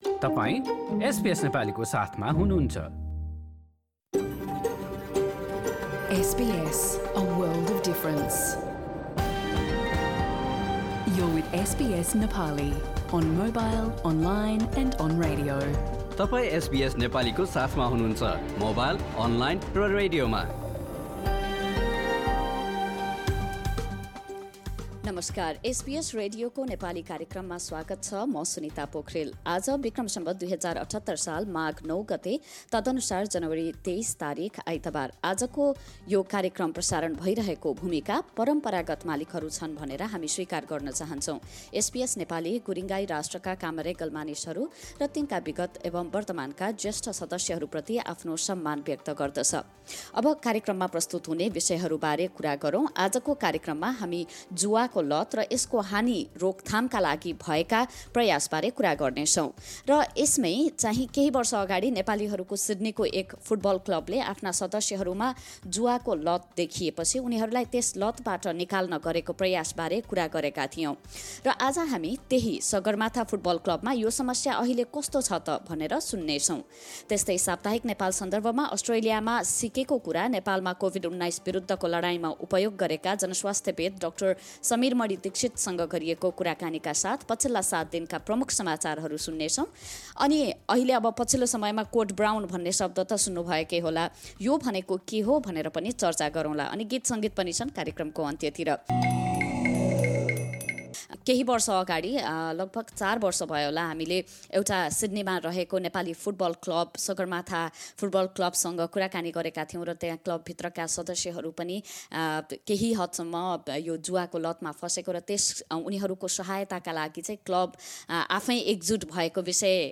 एसबीएस नेपाली रेडियो कार्यक्रम: आइतबार २३ जनवरी २०२२